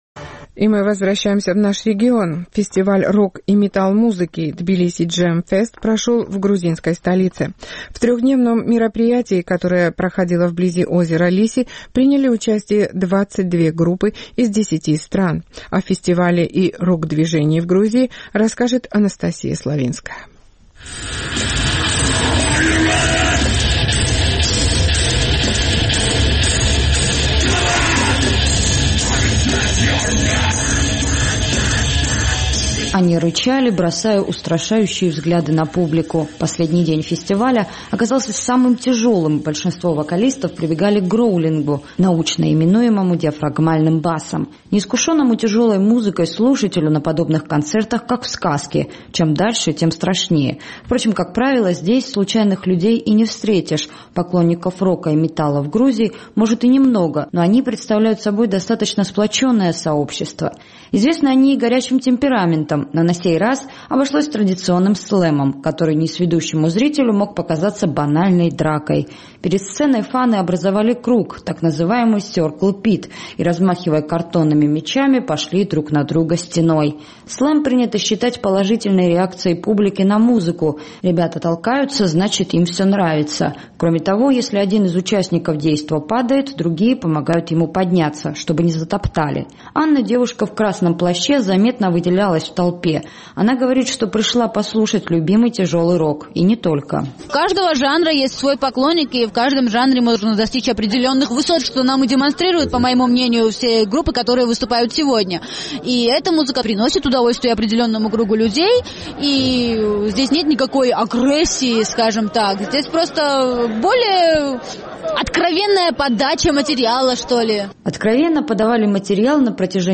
Фестиваль рок- и метал-музыки «Тбилиси Джем Фест» прошел в грузинской столице. В трехдневном мероприятии приняли участие 22 группы из десяти стран.